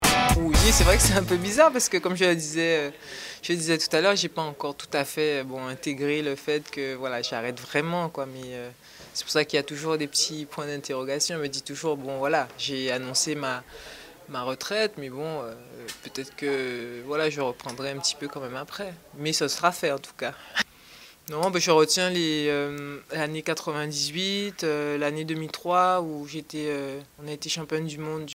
une troisième voix à reconnaître ;)